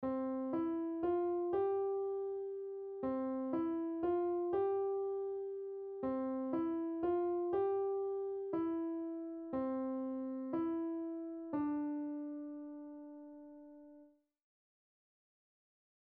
On the piano, play Oh When The Saints Go Marching In (part 1)